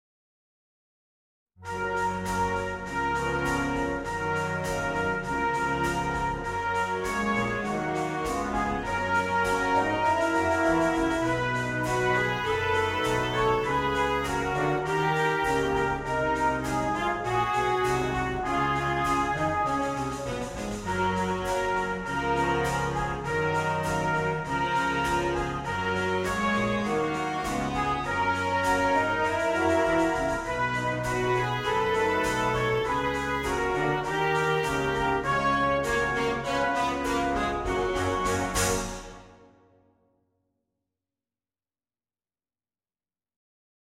на духовой оркестр